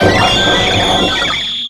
Cri d'Arceus dans Pokémon X et Y.